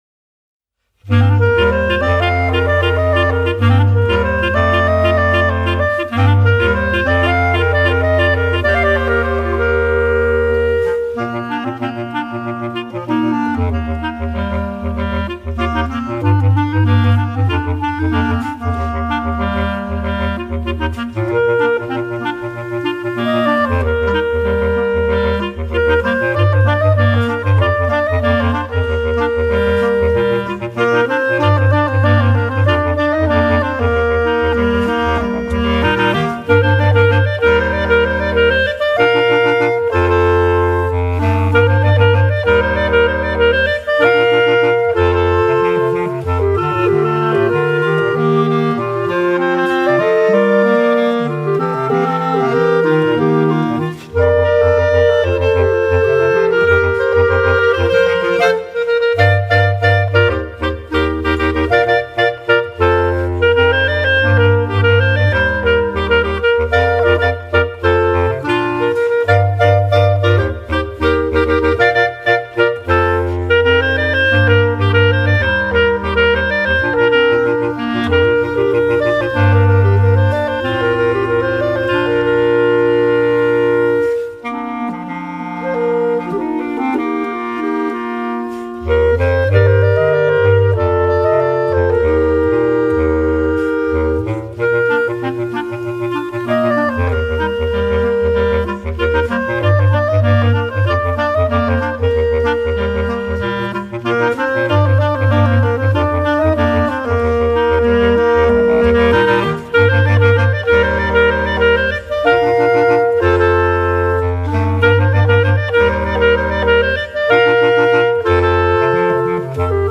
B♭ Clarinet 1 B♭ Clarinet 2 B♭ Clarinet 3 Bass Clarinet
单簧管四重奏
风格： 动漫
快节奏且极具冲击力的音效，一听就让人难以忘怀！